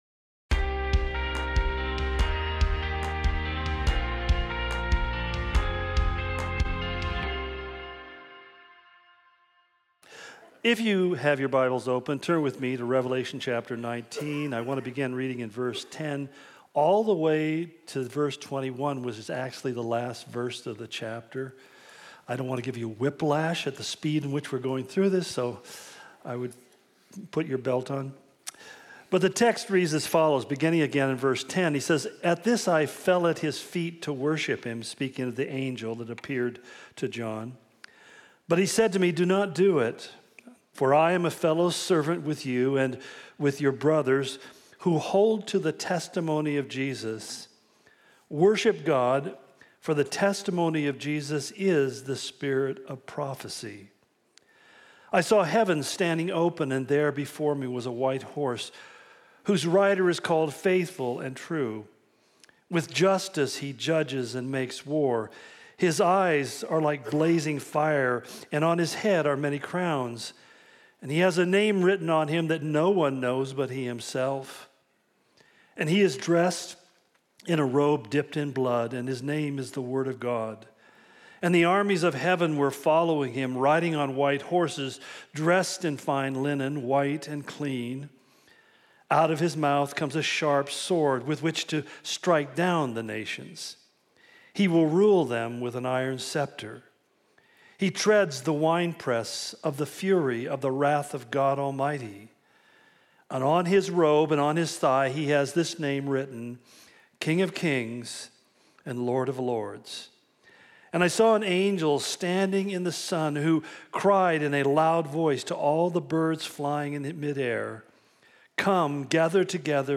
Calvary Spokane Sermon Of The Week podcast